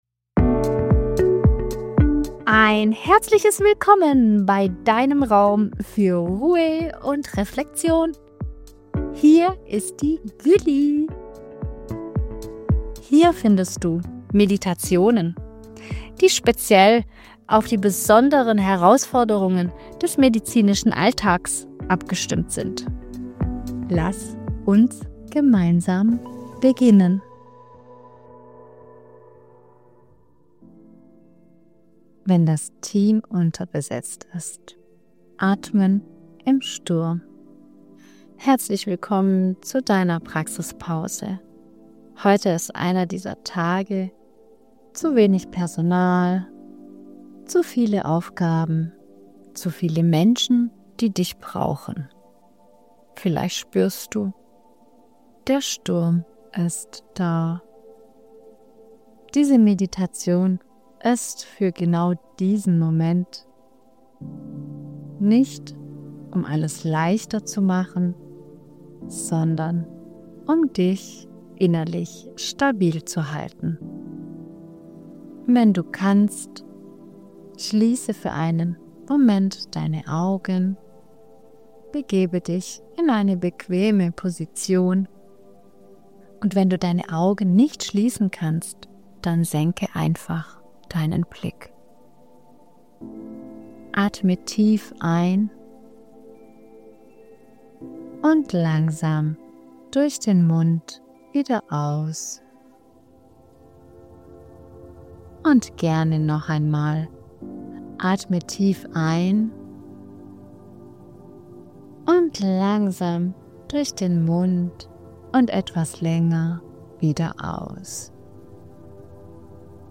eine kurze Meditation für Tage, an denen das Team unterbesetzt